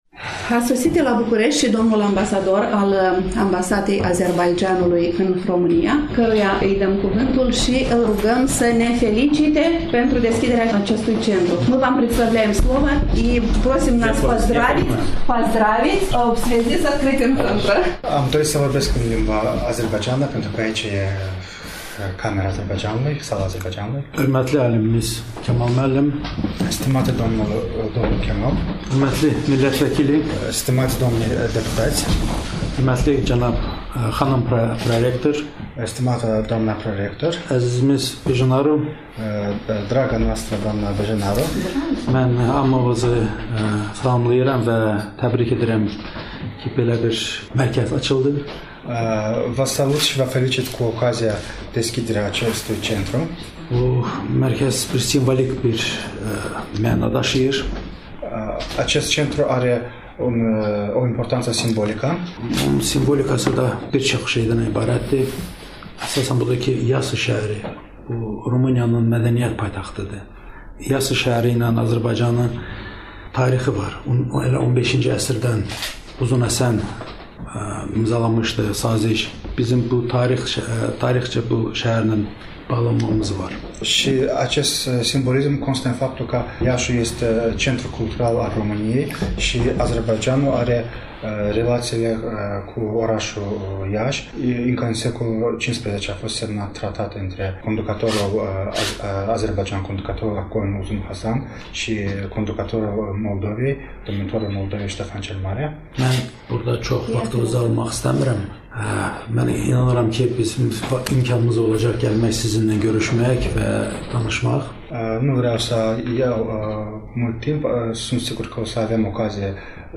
În cele ce urmează, ascultăm discursul Excelenței Sale Doctor Huseyn Najafov, Ambasadorul Extraordinar și Plenipotențiar al Republicii Azerbaidjan în România